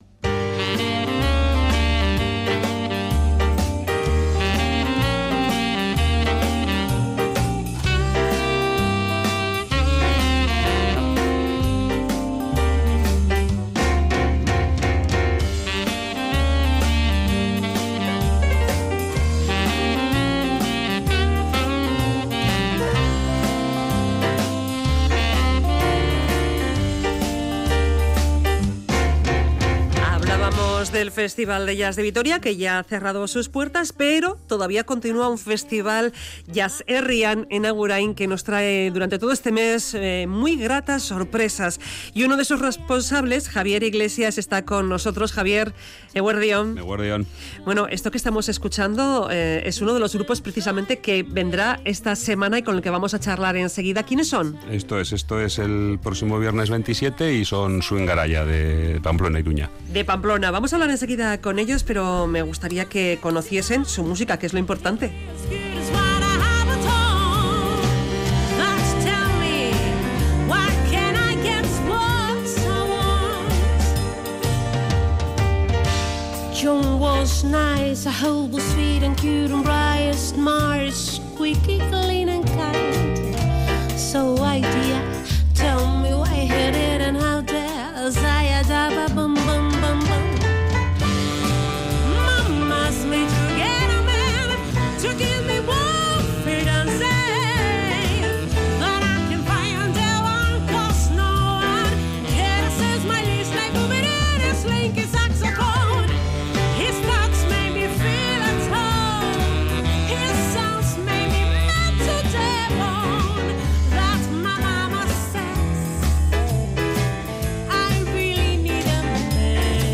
Audio: Su estilo musical de raíces de la música americana, desde el jazz clásico, hasta el jump & jive pasando por el boogie woogie, el rythm & blues o el blues.